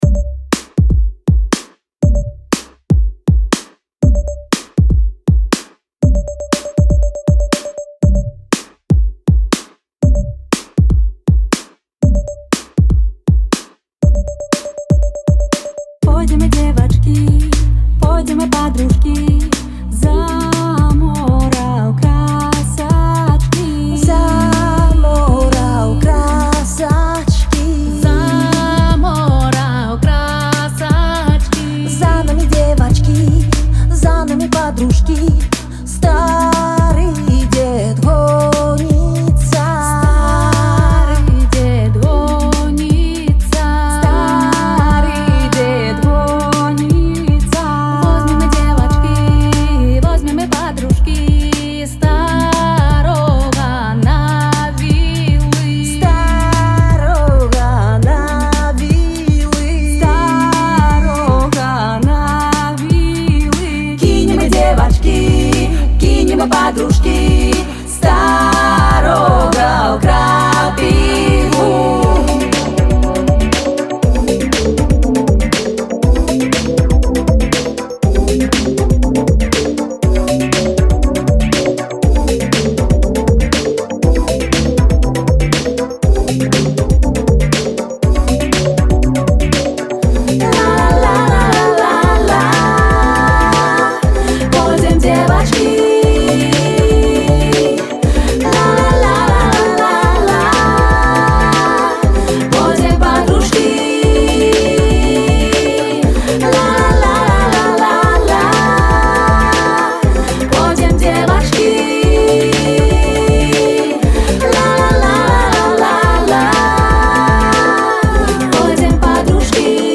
Стылістыка - world-music.
гэта народная песня